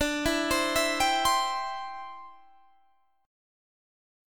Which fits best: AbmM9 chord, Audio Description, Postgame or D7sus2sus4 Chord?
D7sus2sus4 Chord